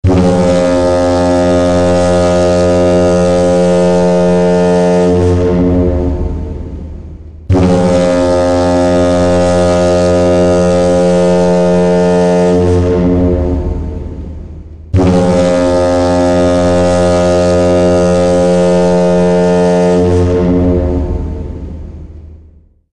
Звуки кораблей
Гул корабельного гудка
Корабельный гул nМорской гудок nГудок корабля